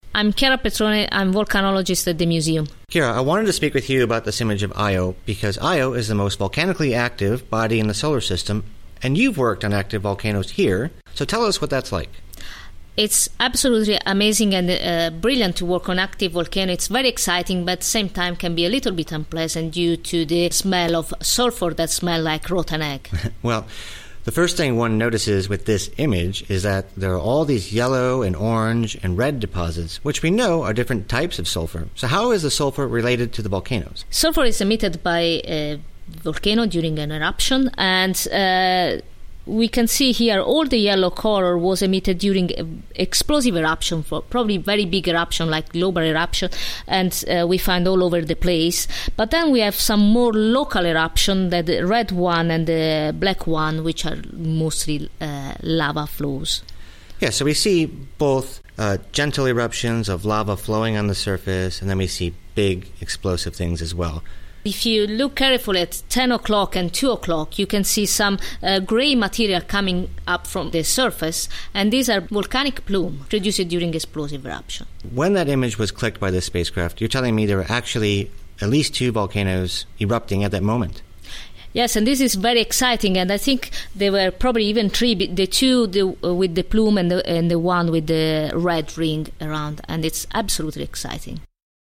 Audio commentary extract Jupiter's moon Io is a similarly striking world.
jupiter-factfile-io-commentary.mp3